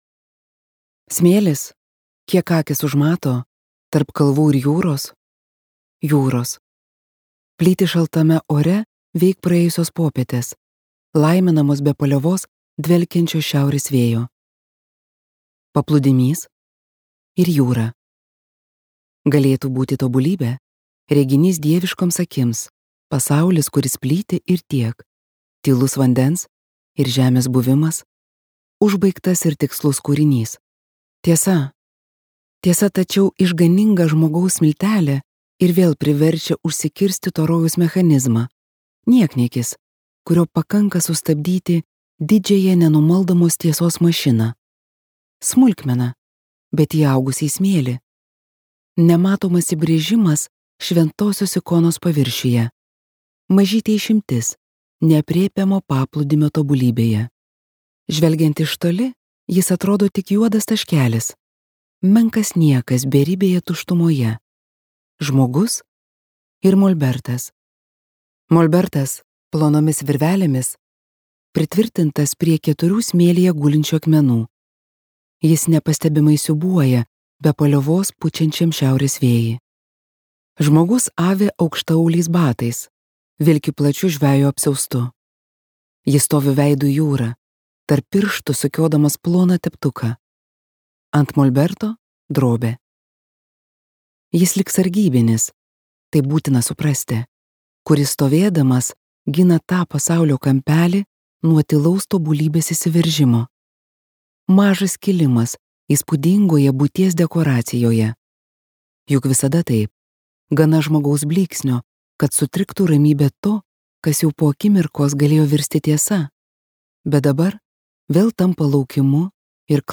Jūra vandenynas | Audioknygos | baltos lankos